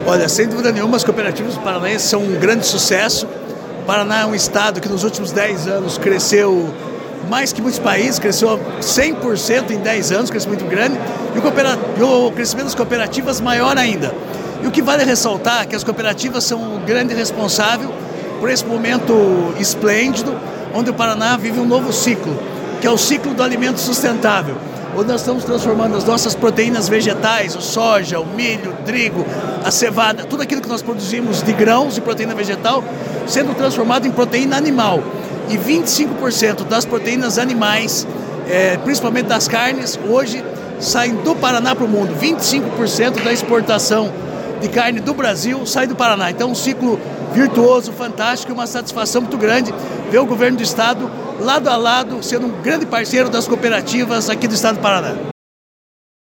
Sonora do secretário da Agricultura e do Abastecimento, Márcio Nunes, sobre o Ano Internacional do Cooperativismo e os 100 anos da Cooperativa Frísia